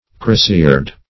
Crosiered \Cro"siered\ (-zh?rd), a. Bearing a crosier.